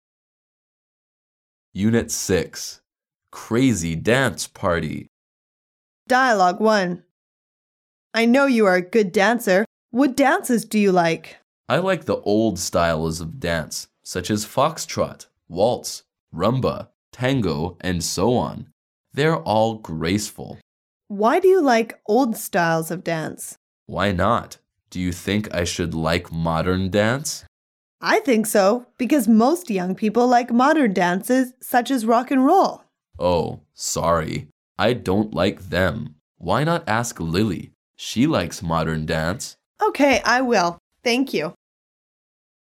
Dialoug 1